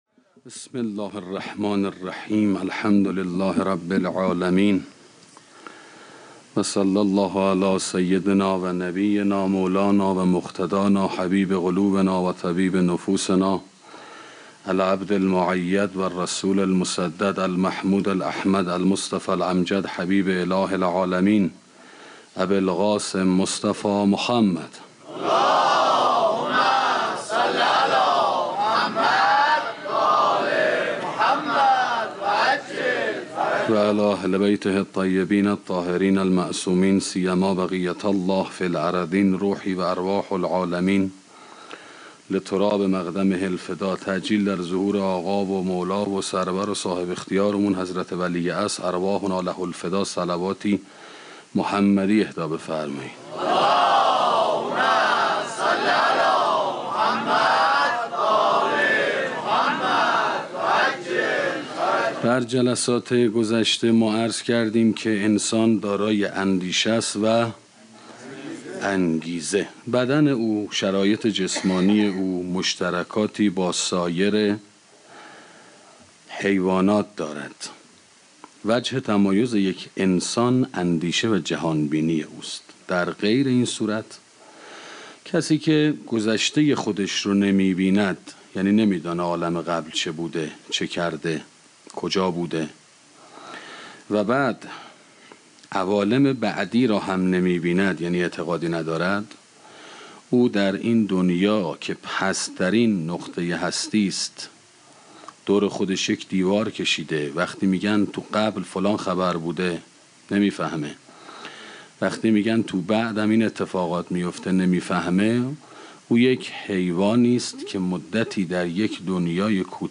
سخنرانی اندیشه و انگیزه 5 - موسسه مودت